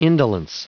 Prononciation du mot indolence en anglais (fichier audio)
Prononciation du mot : indolence